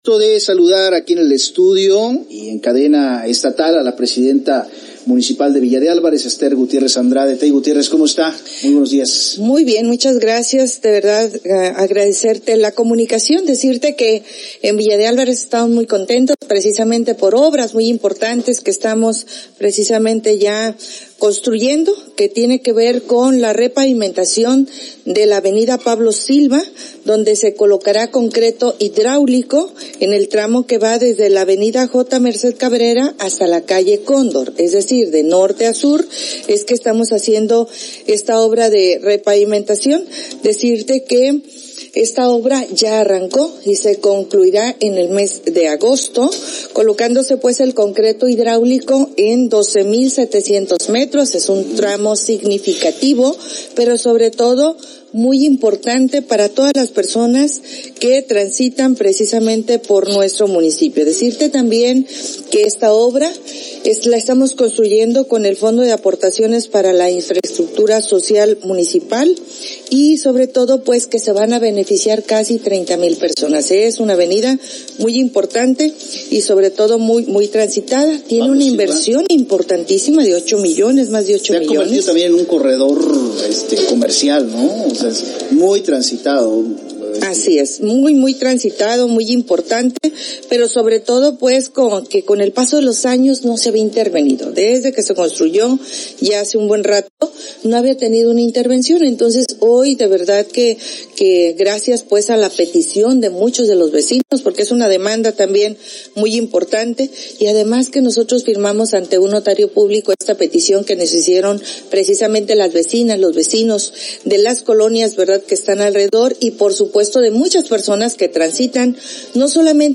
Escucha Informe 24 de lunes a viernes por Súper 98.9FM en la zona metropolitana de Colima, La Max 105.3FM en Tecomán y La Bestia Grupera 93.7 FM en Manzanillo.